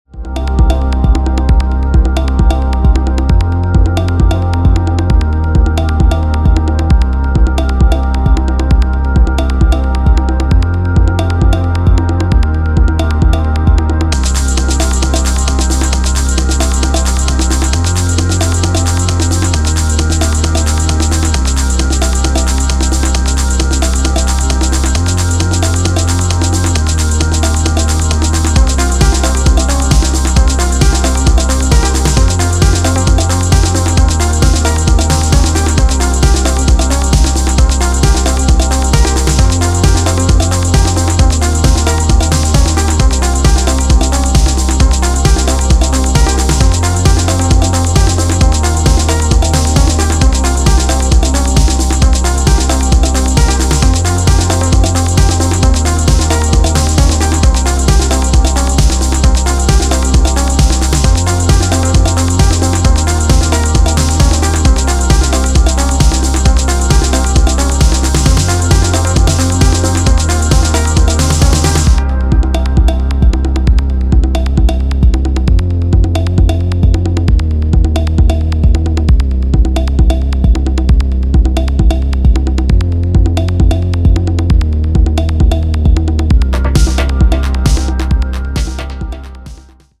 EBM/ニュービート・リヴァイバル以降のピークタイムを直撃するキラー
野外レイヴのクライマックスを想定していそうな、かなり気合の入った内容です。